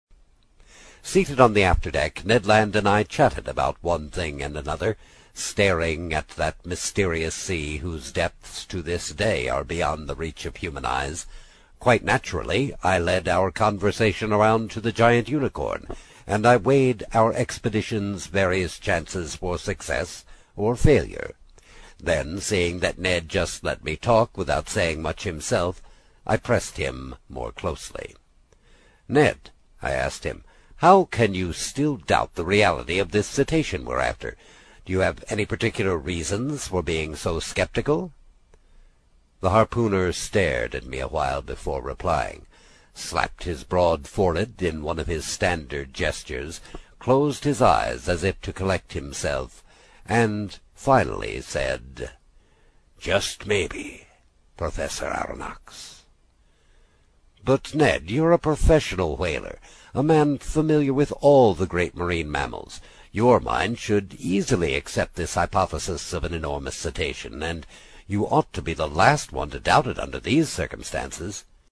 在线英语听力室英语听书《海底两万里》第38期 第4章 尼德兰(7)的听力文件下载,《海底两万里》中英双语有声读物附MP3下载